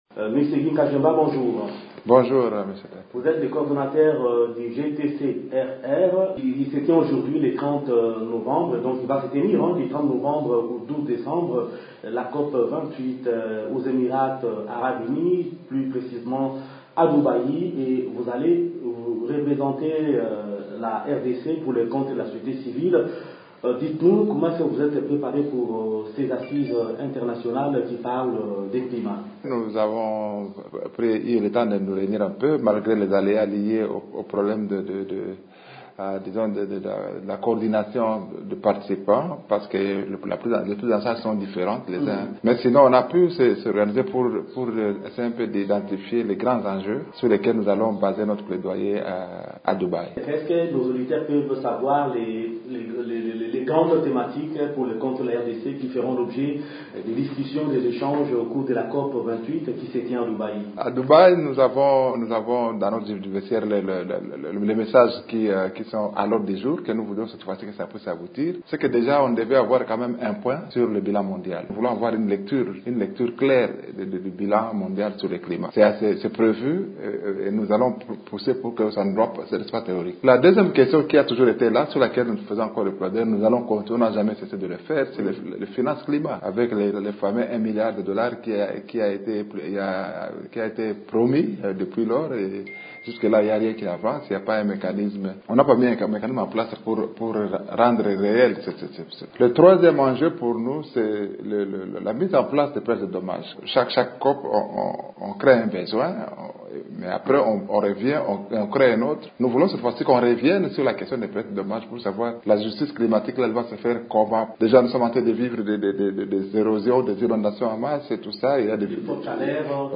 s’entretient sur les grands enjeux de la COP 28